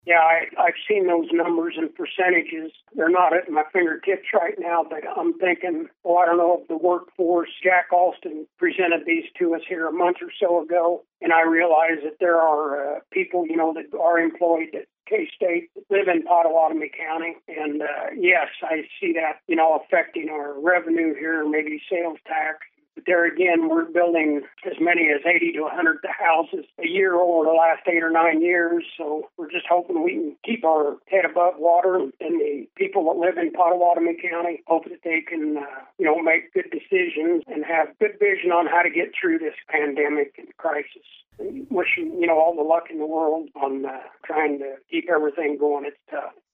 KMAN spoke with Weixelman about his motivation for running for reelection and his stances on various county issues.